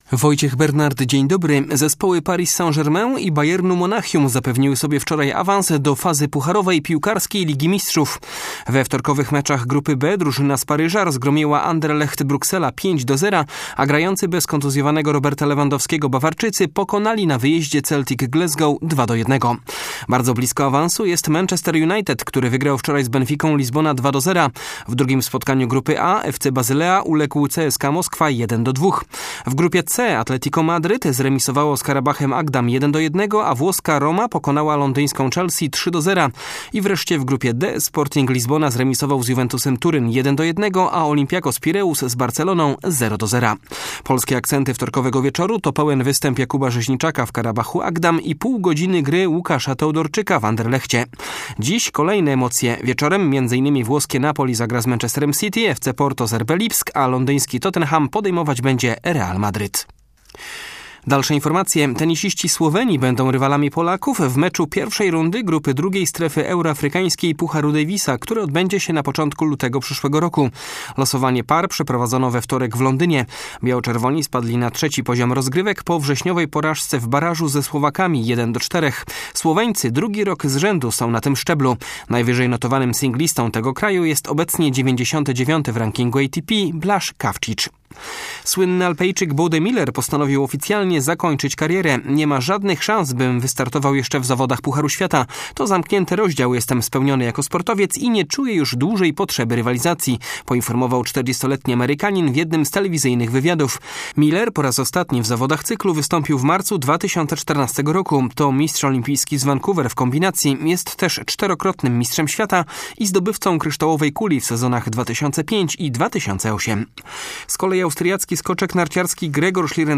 01.11 serwis sportowy godz. 9;05